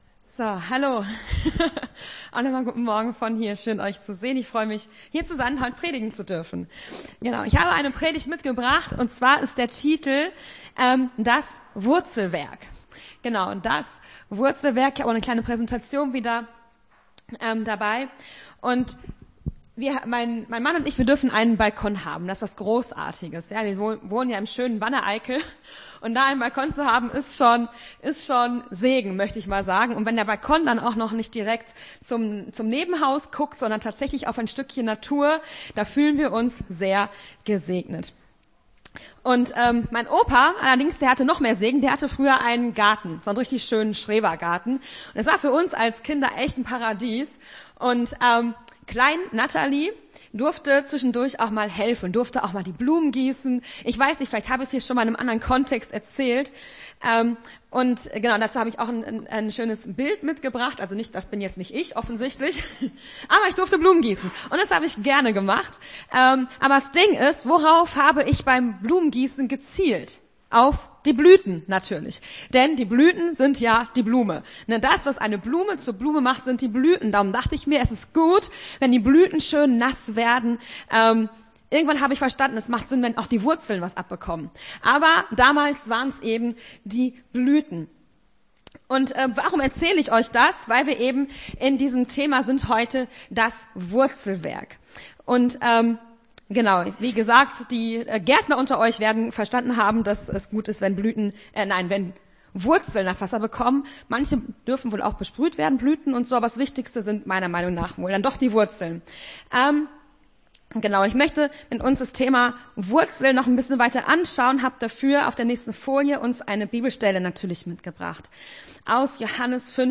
Gottesdienst 29.05.2022 Das Wurzelwerk